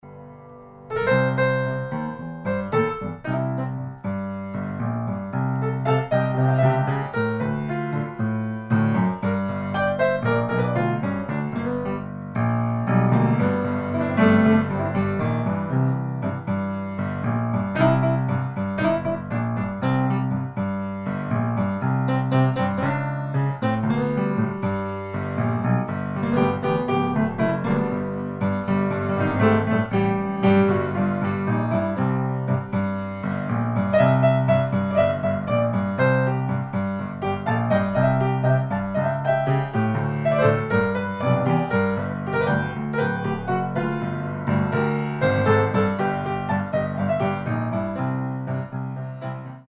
Style: New Orleans Piano